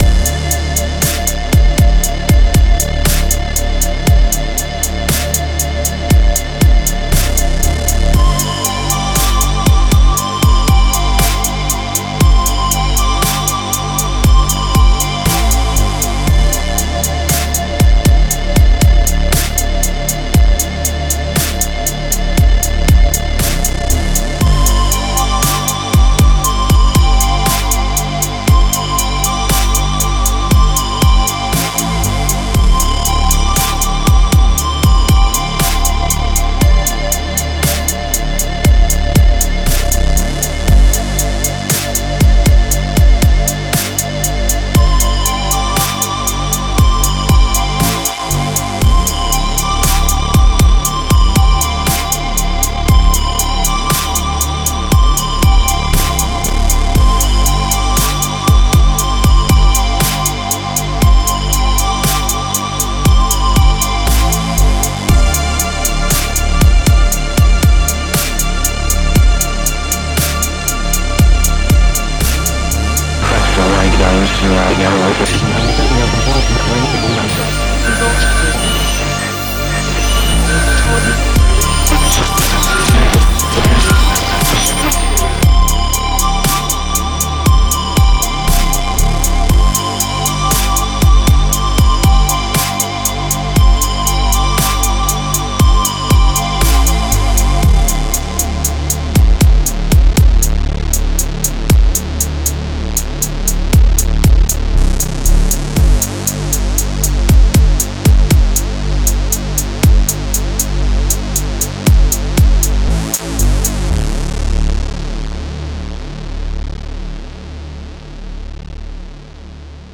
Жанр: Electronic, Italo Disco, Synth Pop, New Wave